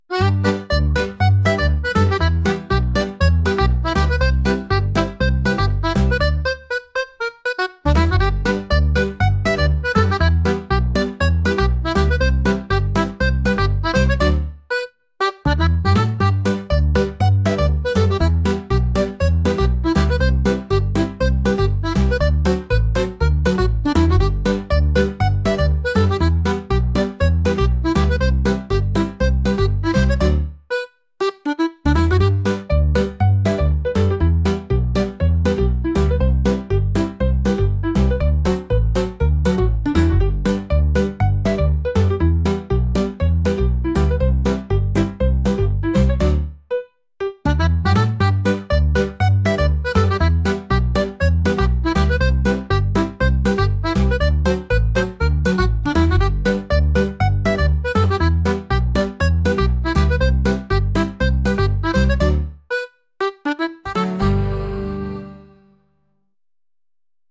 朝急いでいて食べる暇はないけど、食べてと誘惑してくるアコーディオンの音楽です。